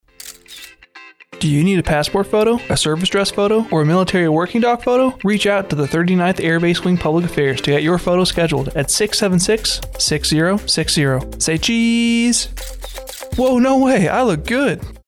AFN INCIRLIK RADIO SPOT: Scheduling a Studio Photo